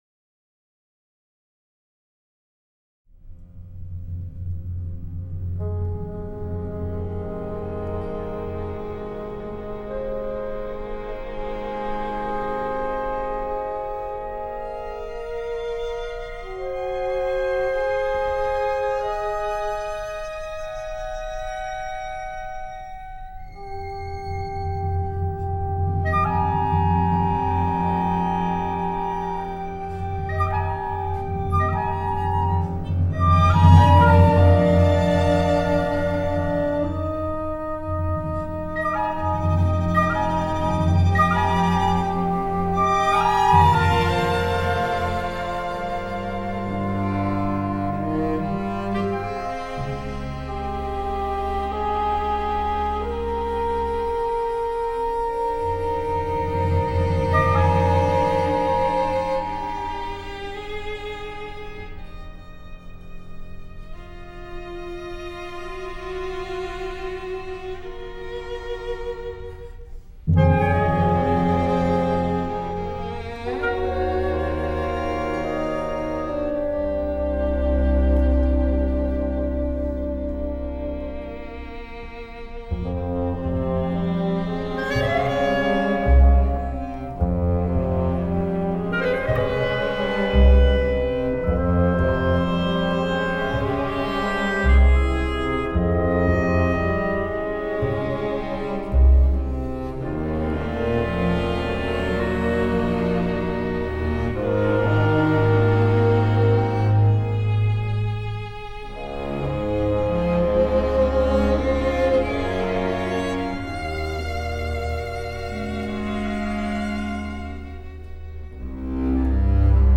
Performance by the New York University Steinhardt School of Performing Arts Orchestra, Frederick Loewe Theater - 35 West 4th Street, New York City.
A chamber piece for nine instruments
Eurydice-a-chamber-piece-for-9-instruments.m4a